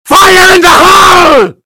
Fire In The Hole Loud Sound Effect Free Download
Fire In The Hole Loud